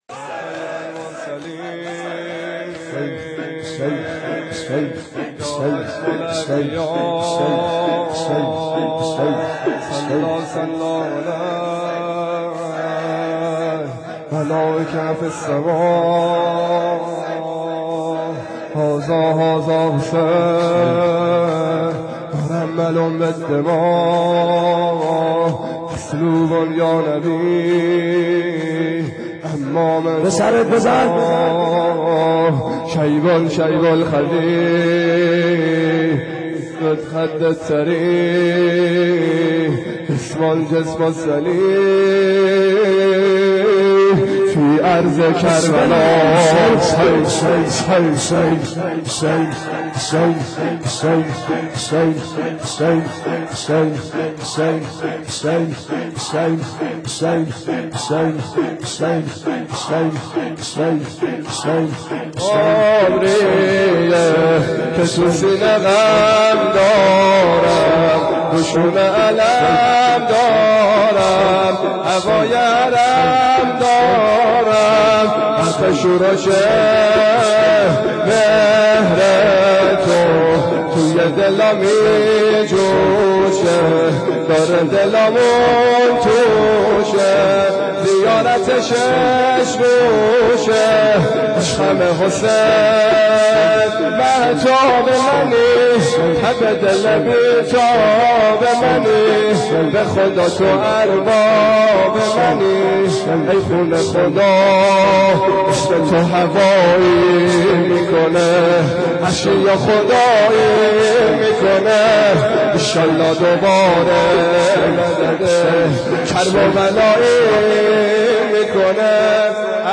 شب یازدهم _ شور